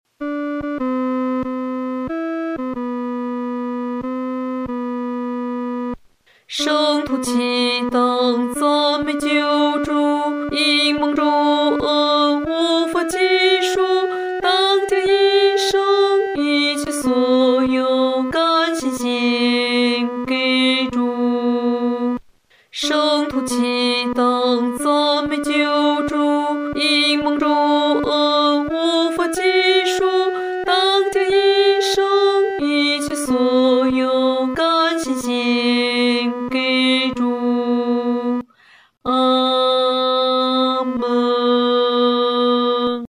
女低